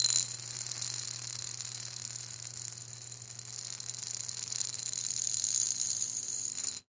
金属 " 金属环01
描述：金属碗，有像样的响声。 高音
Tag: 金属